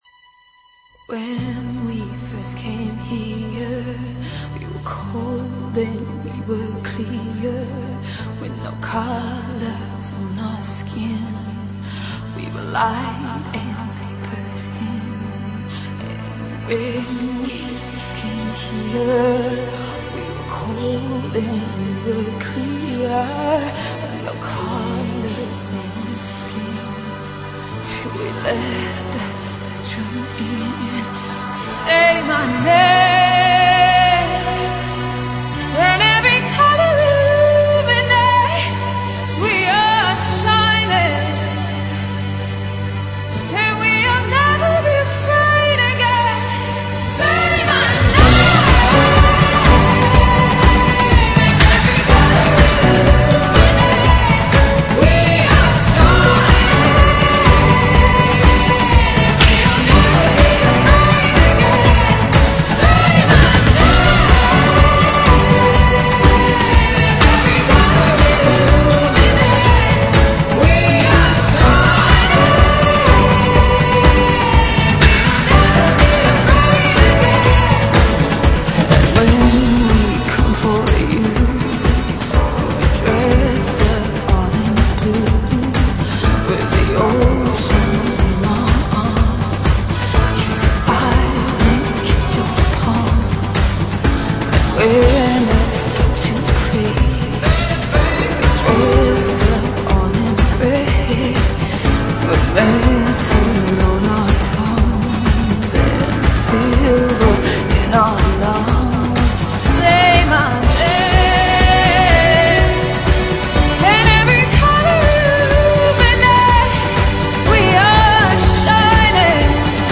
Dance Para Ouvir: Clik na Musica.